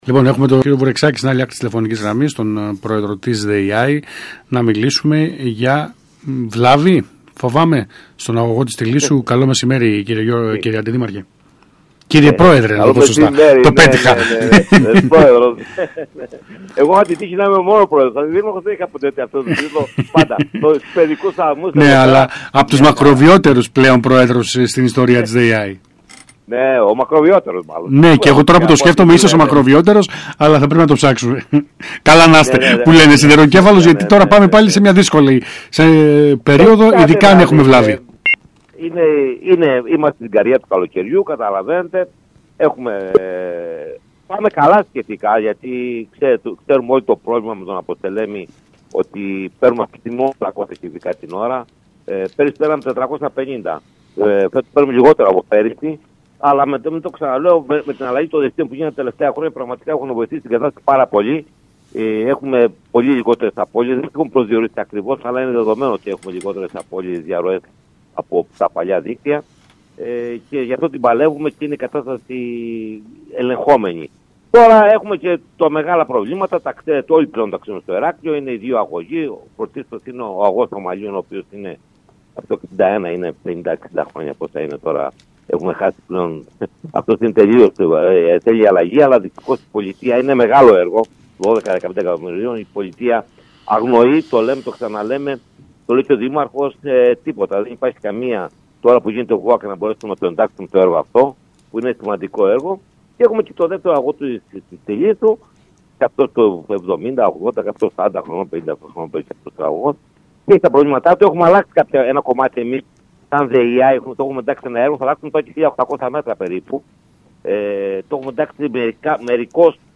Ακούστε εδώ όσα είπε ο Πρόεδρος της ΔΕΥΑΗ Γιώργος Βουρεξάκης στον ΣΚΑΙ Κρήτης 92.1: